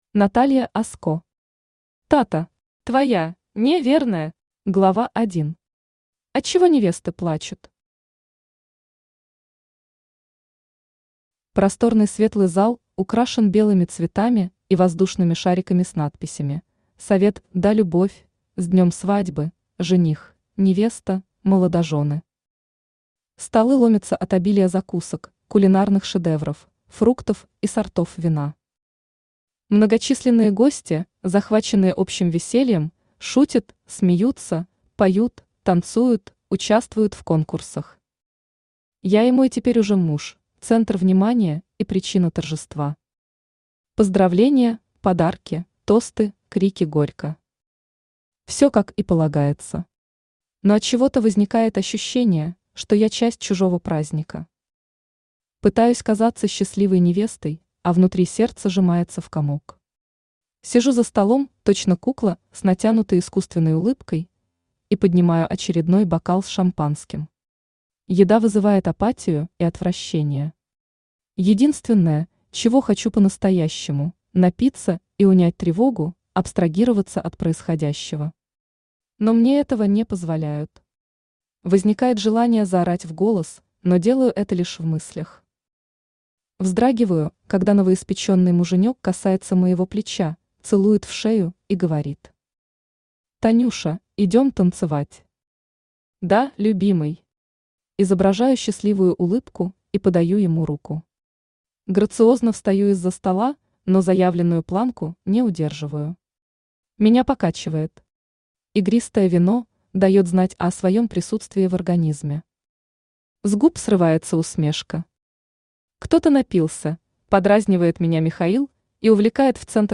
Аудиокнига Тата. Твоя (не)верная | Библиотека аудиокниг
Твоя (не)верная Автор Наталья Оско Читает аудиокнигу Авточтец ЛитРес.